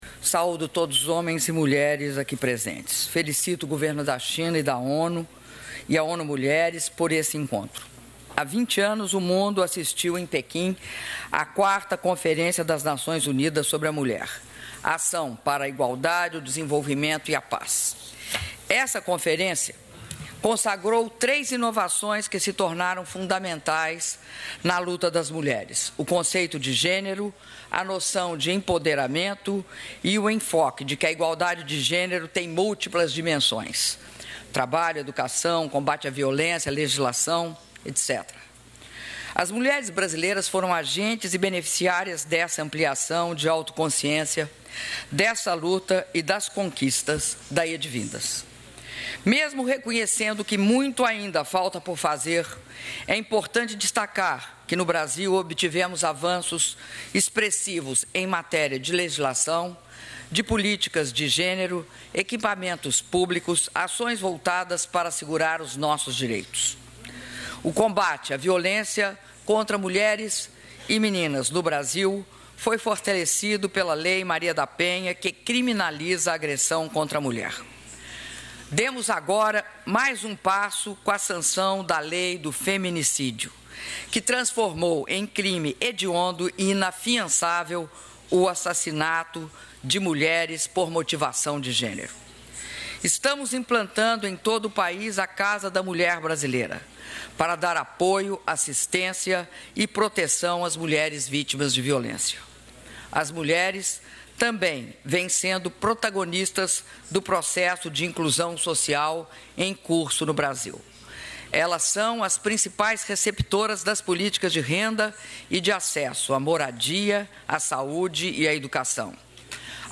Áudio do discurso da presidenta da República, Dilma Roussef, durante Encontro de líderes globais sobre igualdade de gênero e empoderamento das mulheres: um compromisso para a ação - Nova Iorque/EUA (5min16s)